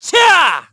Siegfried-Vox_Attack3_kr_b.wav